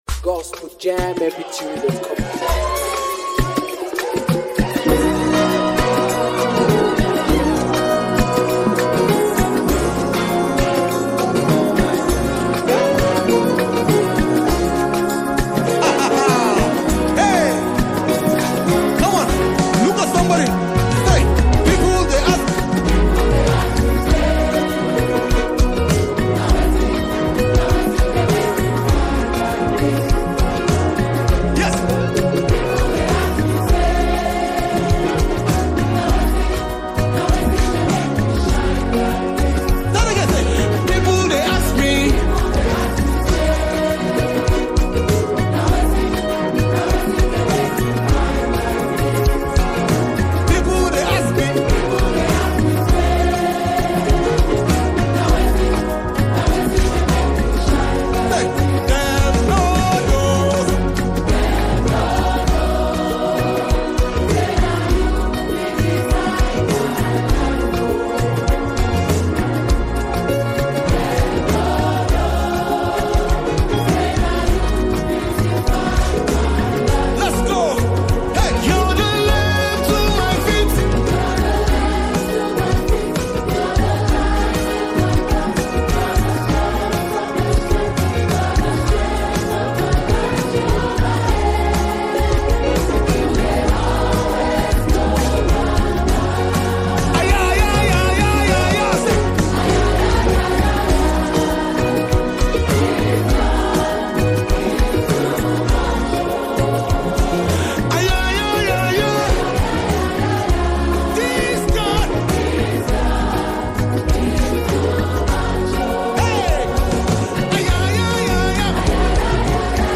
Lined with soulful melodies and inspiring lyrics